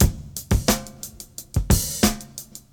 88 Bpm Drum Loop Sample E Key.wav
Free drum loop sample - kick tuned to the E note. Loudest frequency: 2558Hz
88-bpm-drum-loop-sample-e-key-Ikp.ogg